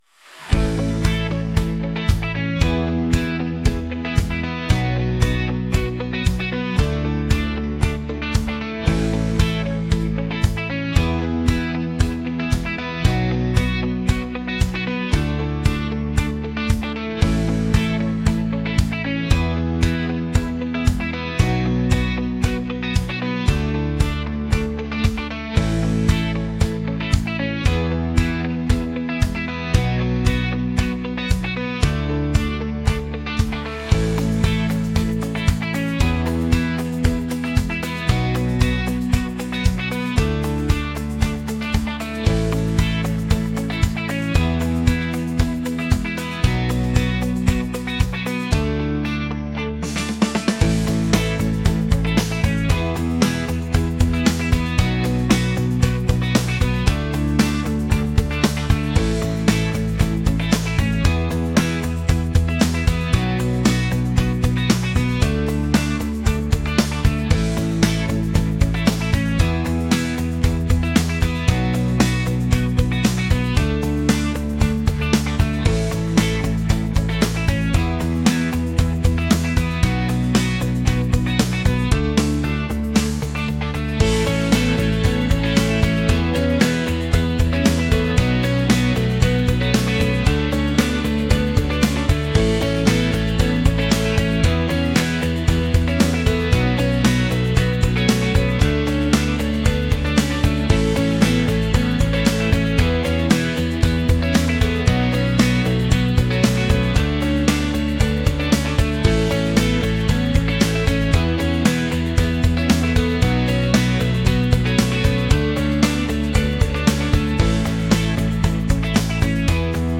catchy | upbeat | pop